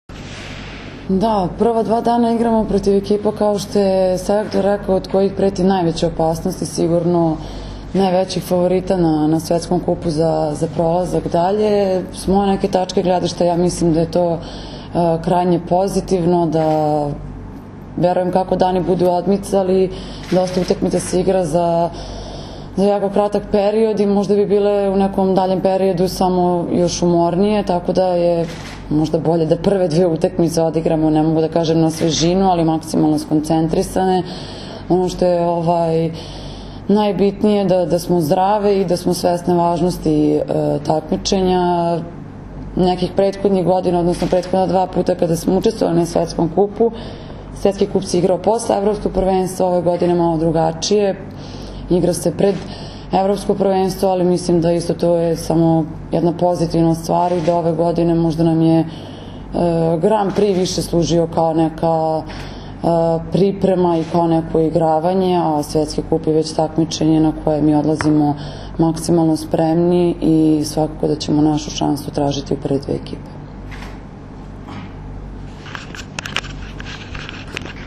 Tim povodom danas je u beogradskom hotelu „M“ održana konferencija za novinare, kojoj su prisustvovali Zoran Terzić, Maja Ognjenović, Jelena Nikolić i Milena Rašić.
IZJAVA MAJE OGNJENOVIĆ